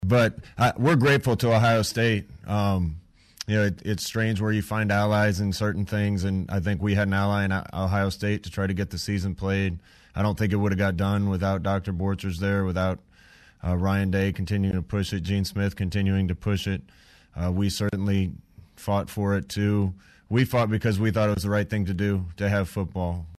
Coach Frost spoke to the media about finding an unlikely ally in a conference rival.